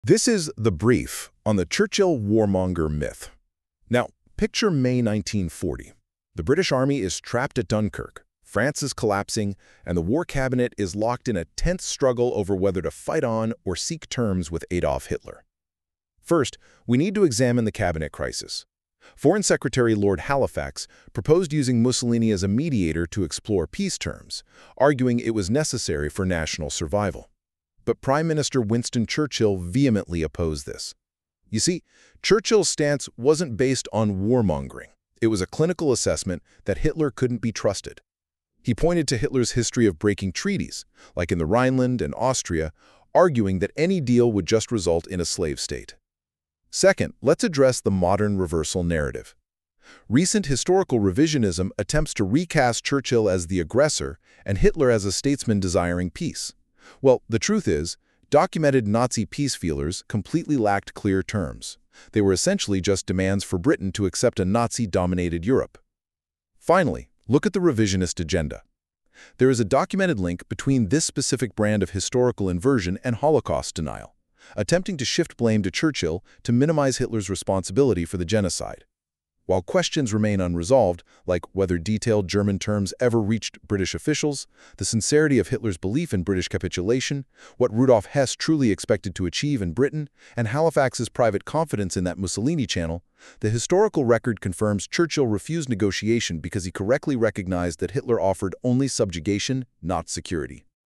Terminal Access: AI Audio Briefing
This is an experimental AI Audio Briefing, synthesised from Veriarch investigative archives to provide a concise, objective summary of the documentation.